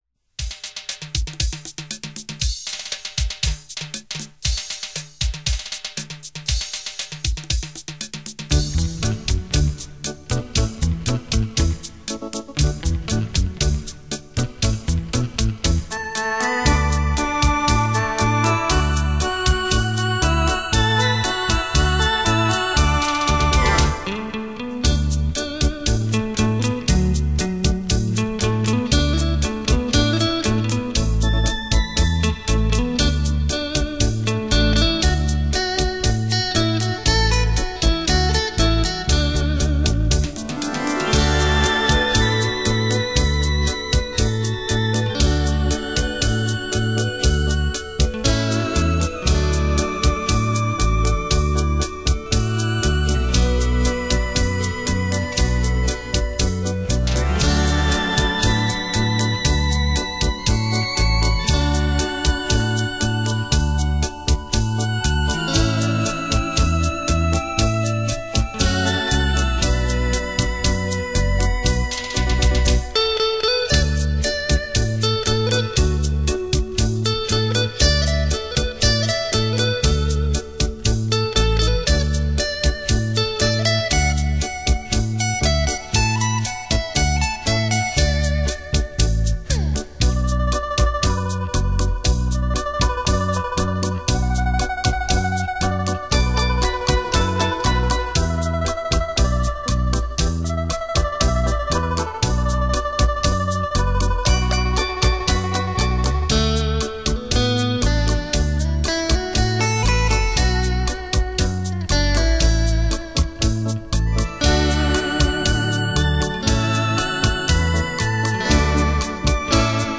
本张专辑系列采用的是，结合了最新音频分离与合成技术和软件音源模拟
冲击力超强。